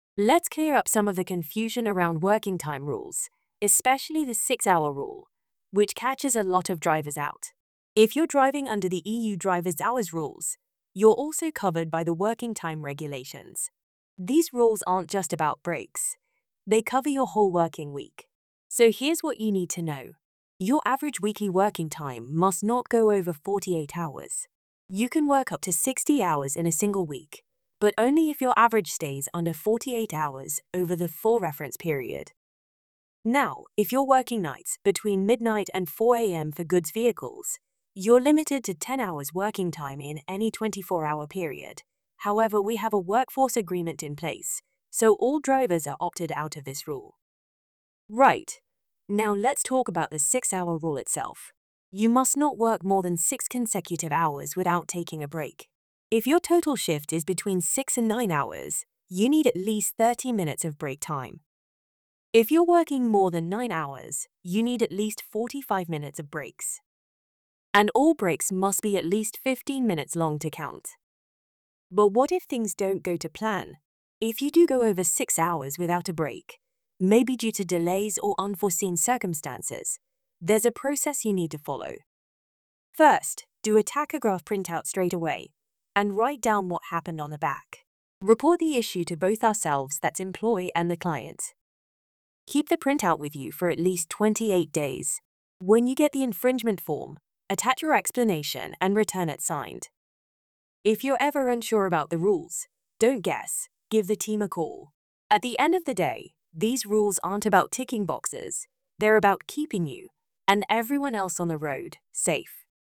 We know regulations can be a bit of a headache – that’s why we’ve recorded an easy audio explainer just for you.